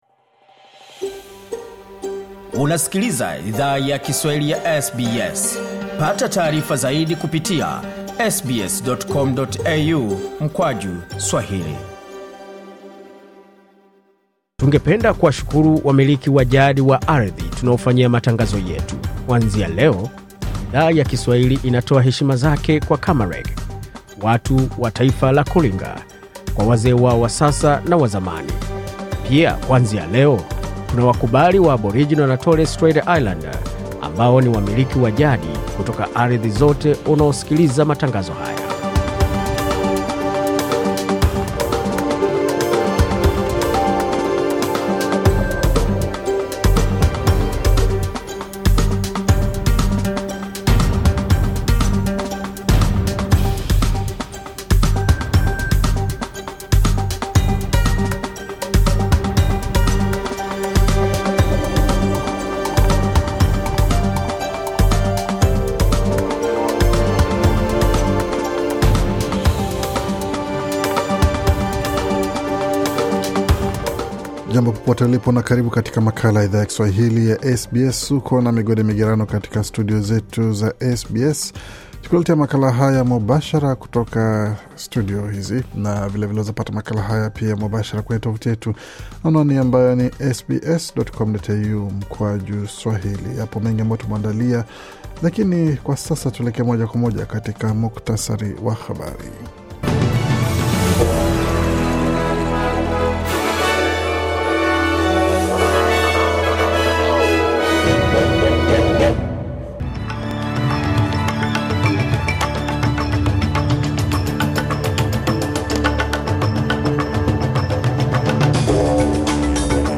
Taarifa ya Habari 6 Mei 2025